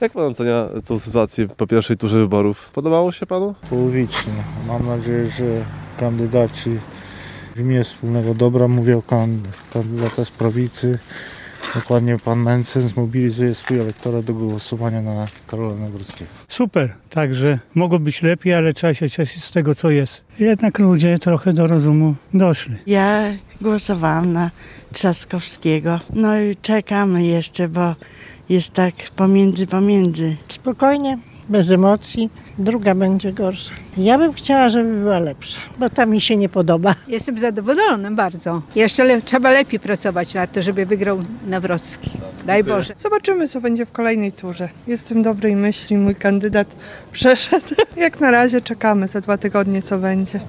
Region: Sonda: Nastroje mieszkańców po I turze wyborów prezydenckich
Mieszkańcy regionu, z którymi rozmawiał nasz reporter są w większości zadowoleni z wyniku niedzielnego głosowania.